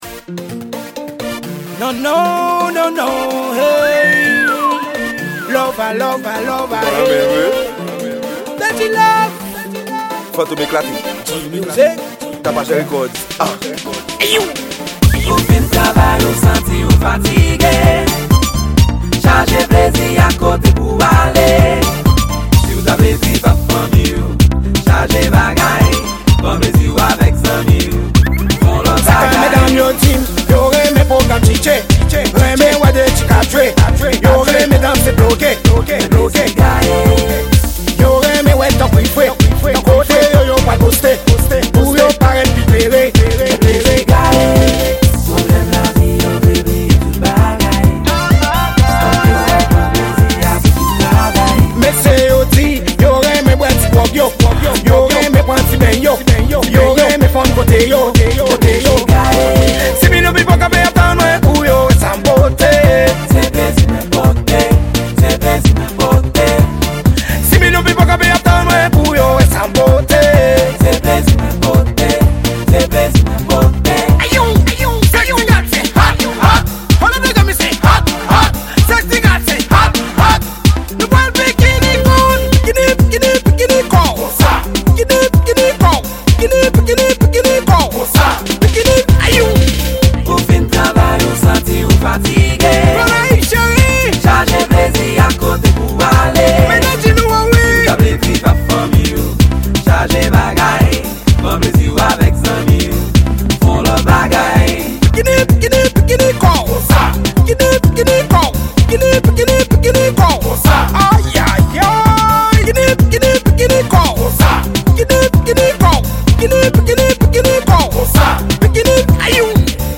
Genre: Dancehall.